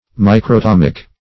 Search Result for " microtomic" : The Collaborative International Dictionary of English v.0.48: Microtomic \Mi`cro*tom"ic\, Microtomical \Mi`cro*tom"ic*al\, a. Of or pert. to the microtome or microtomy; cutting thin slices.